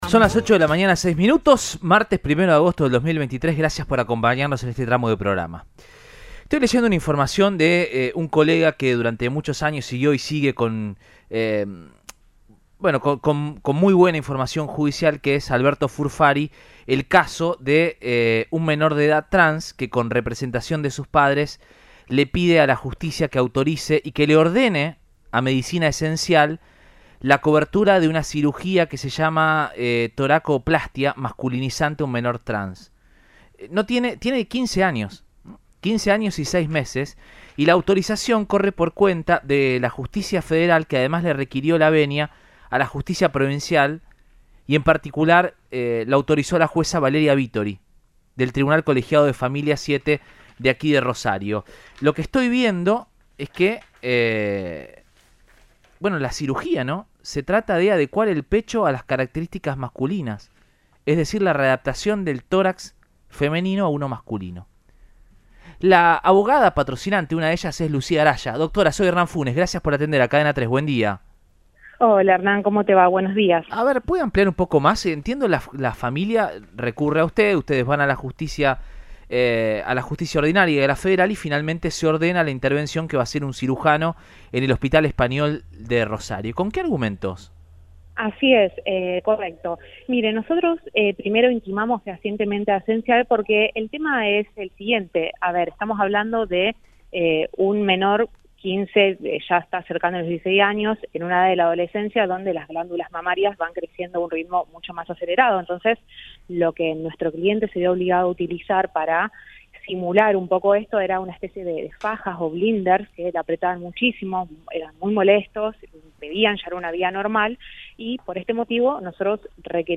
brindó una entrevista a Radioinforme 3, por Cadena 3 Rosario, y contó: “La operación se hizo a principios de mes y salió con éxito.